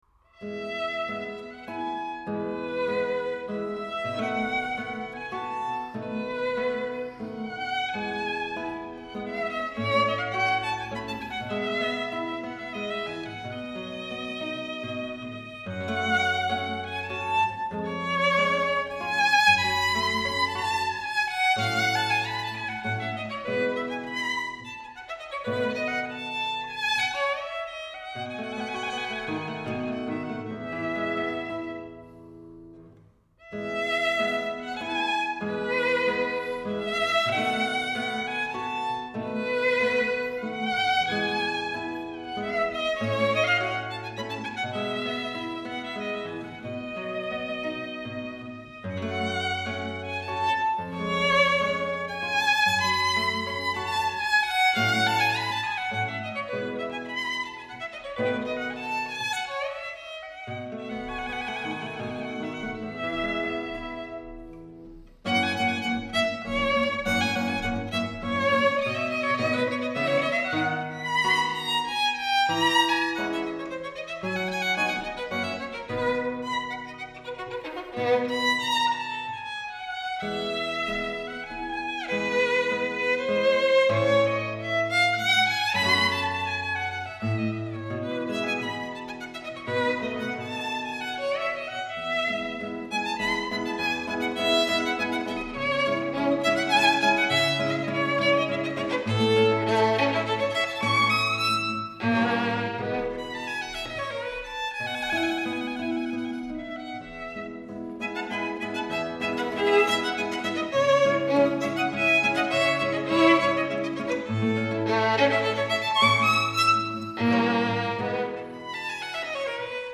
名演当然要有名琴来搭配。
美妙的小提琴音配上绝美的旋律，无论当背景音乐聆听或是认真欣赏，都可以感受到这些乐曲中优雅、华丽而高贵、深情的音乐魅力。